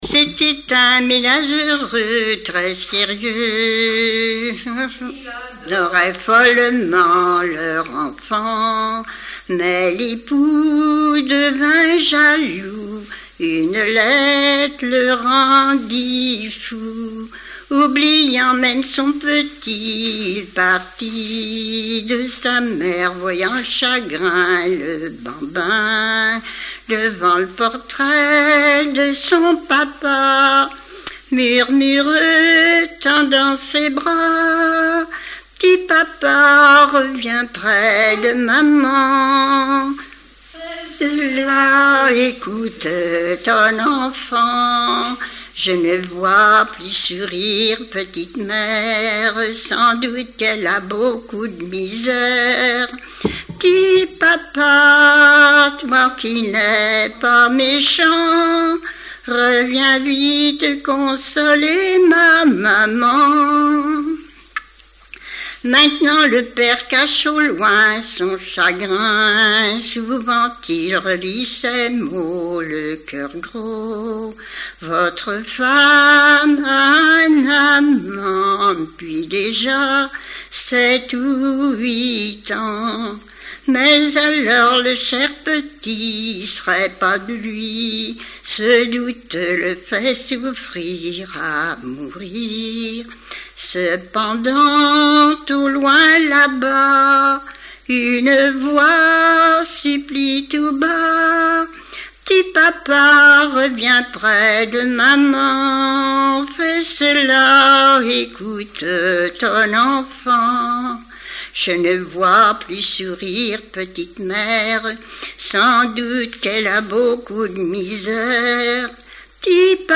Bernardière (La)
Répertoire de chansons populaires et traditionnelles
Pièce musicale inédite